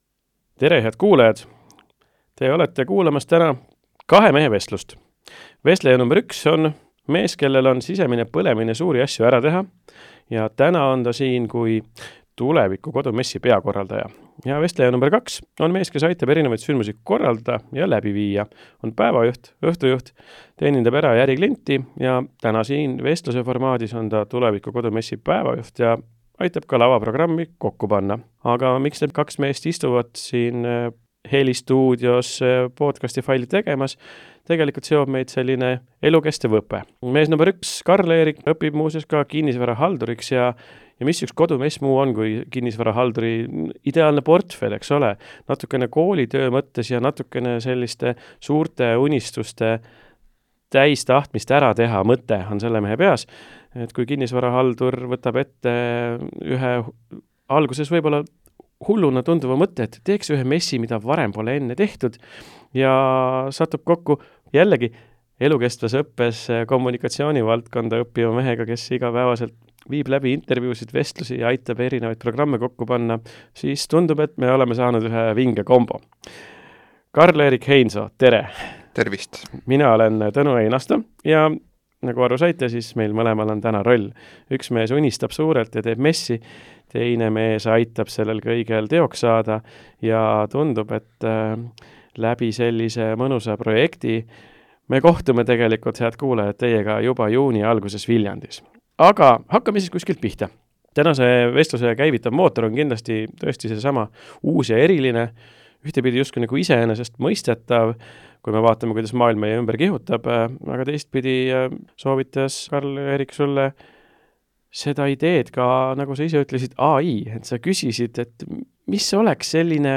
Tuleviku Kodu Messi erisaade!See ei ole lihtsalt jutt messist – see on vestlus sellest, kuidas sünnib midagi täiesti uut ja tulevikku vaatavat.